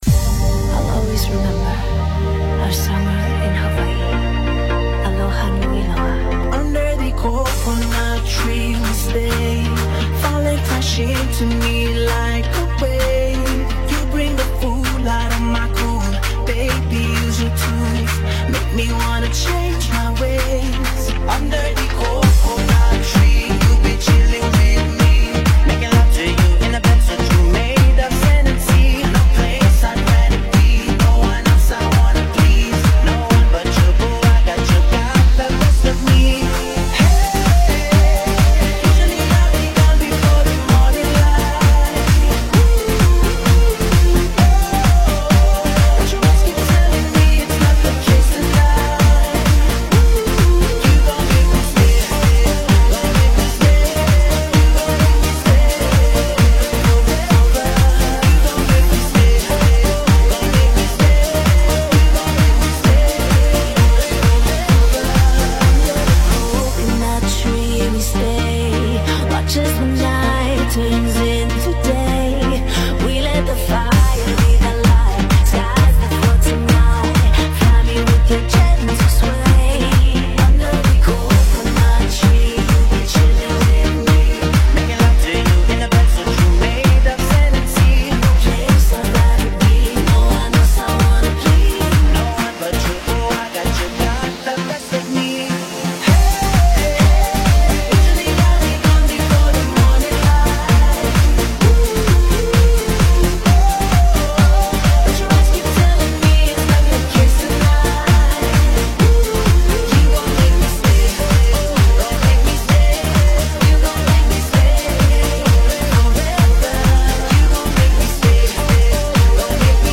seleção mixada